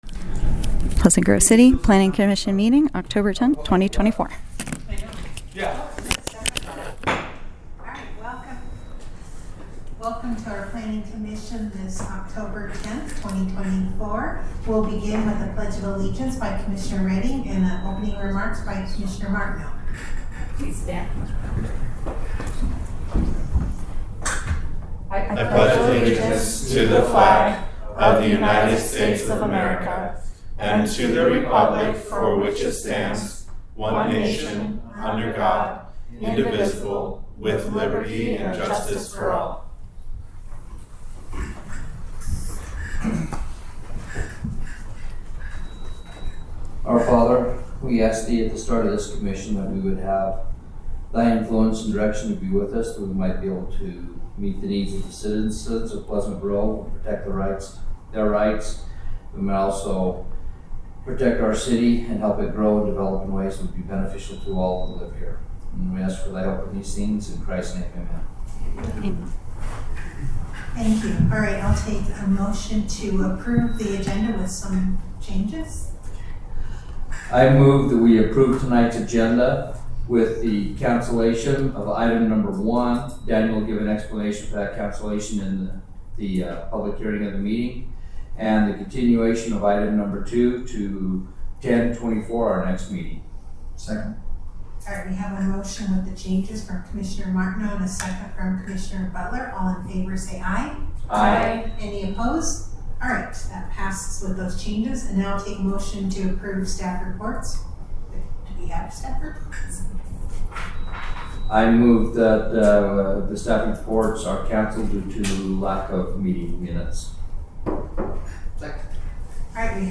Planning Commission Meeting Agenda
Community Room